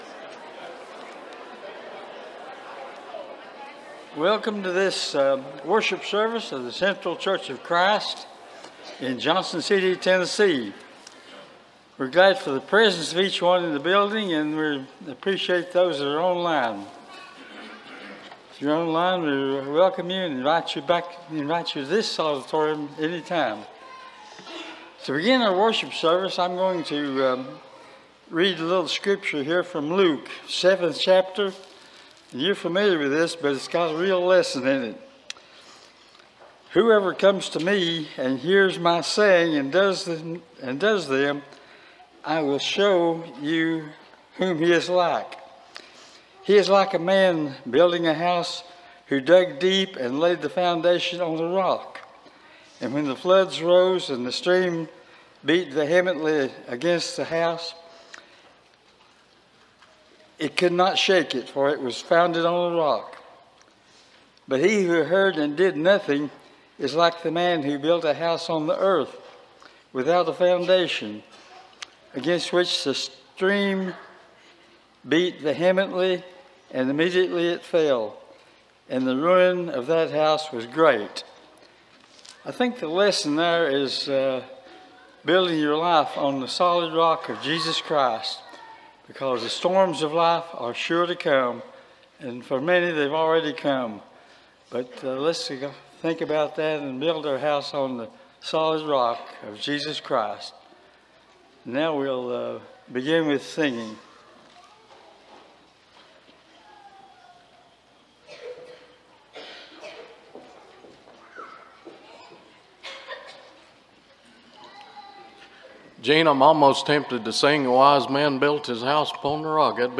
Matthew 2:10, English Standard Version Series: Sunday AM Service